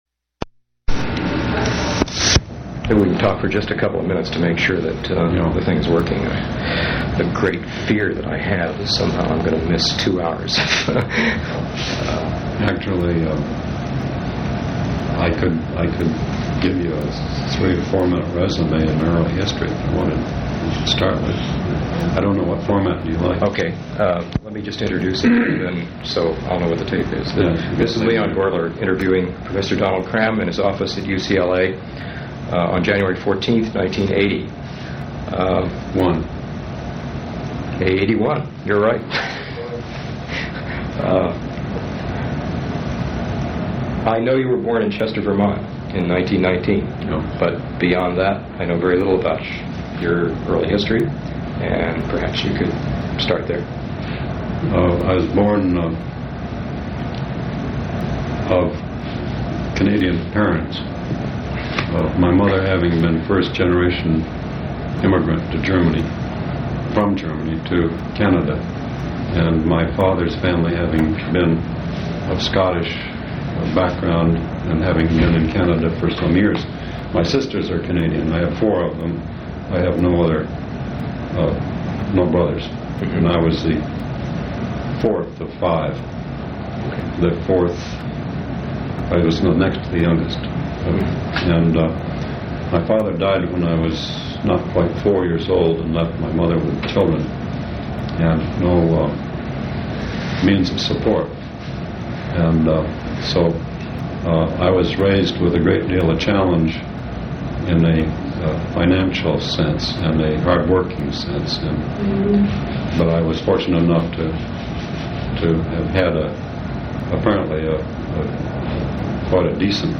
Oral history interview with Donald J. Cram